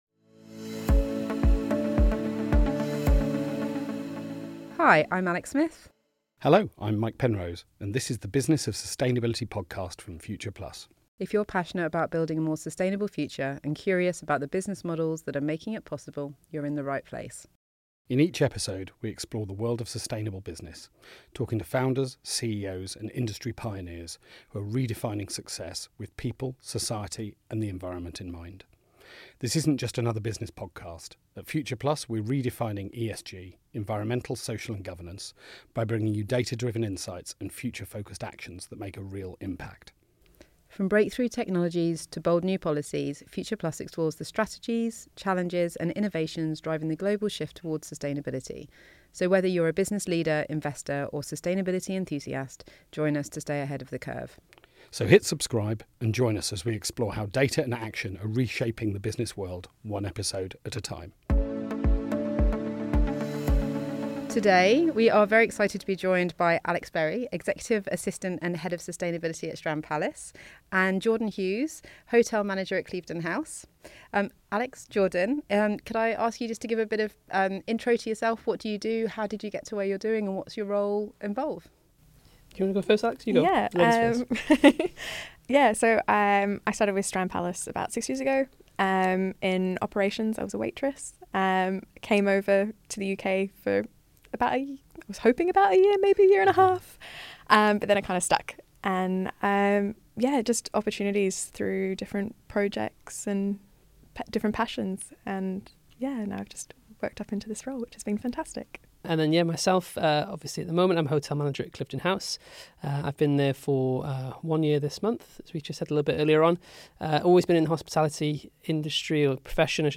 Whether you're a hotelier, sustainability leader, or conscious traveller, this is a conversation not to miss.